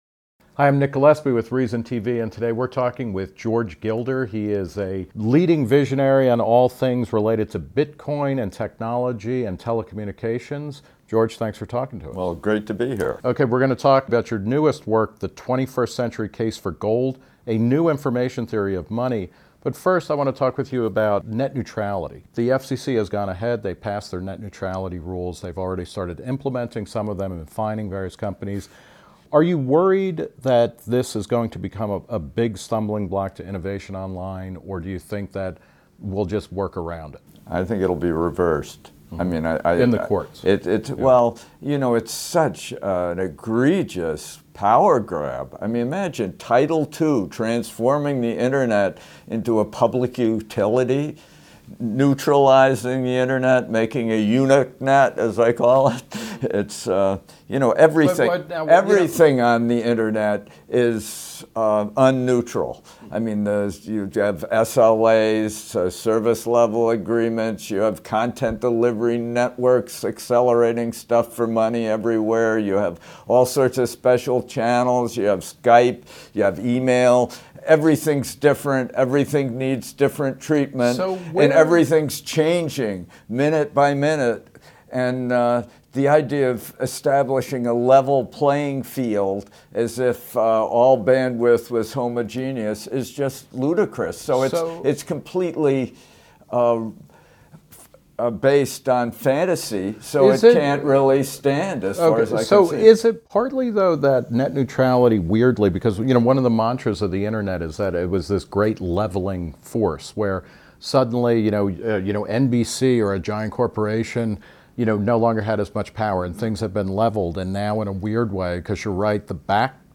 Gilder sat down with Reason TV's Nick Gillespie at FreedomFest 2015 to talk about net neutrality and his new book, The 21st Century Case for Gold: A New Information Theory of Money, which argues that Bitcoin is the missing eighth layer of the Internet.